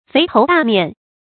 肥頭大面 注音： ㄈㄟˊ ㄊㄡˊ ㄉㄚˋ ㄇㄧㄢˋ 讀音讀法： 意思解釋： 見「肥頭大耳」。